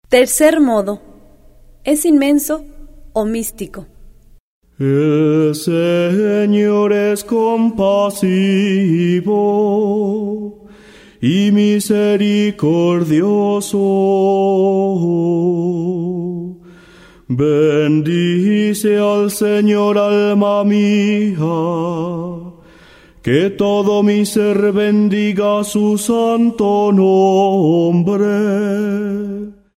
04 Tercer modo gregoriano.